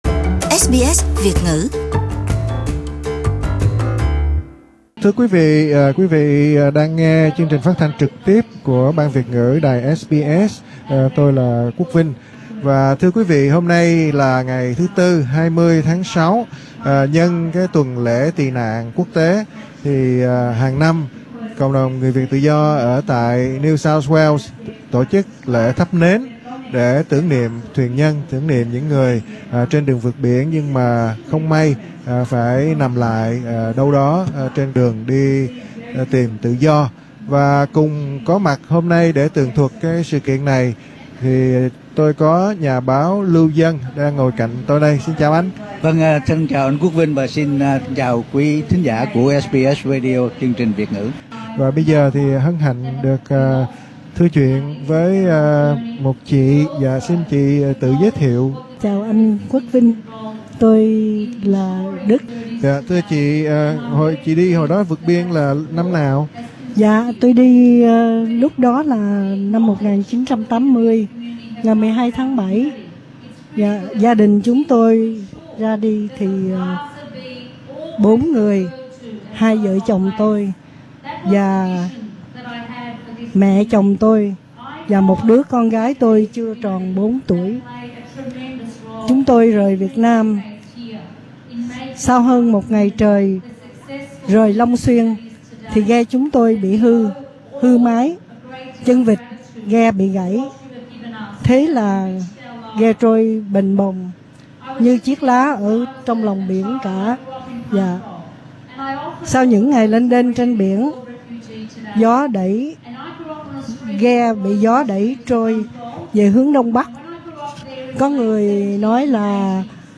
Hai nữ cựu thuyền nhân bồi hồi nhớ lại chuyến vượt biển kinh hoàng với những mất mát to lớn trên đi tìm tự do.
tại buổi phát thanh trực tiếp của SBS Radio ở Saigon Place, Bankstown